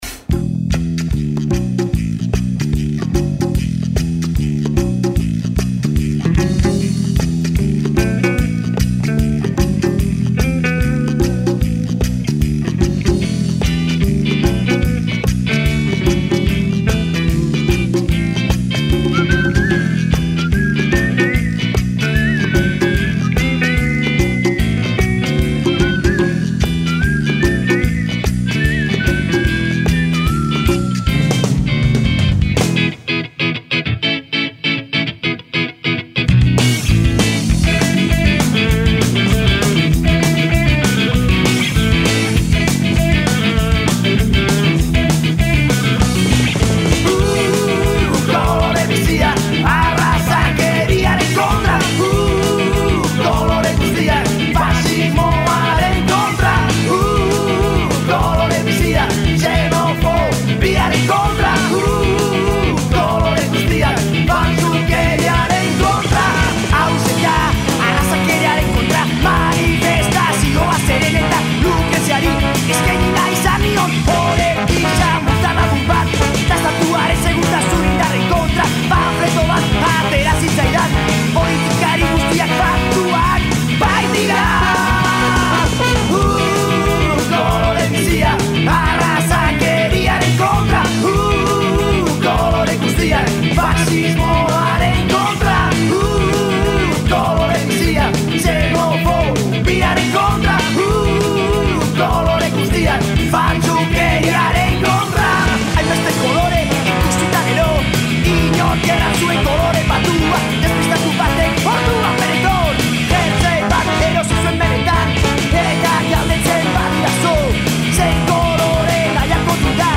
Un viaggio musicale dentro le culture latino americane.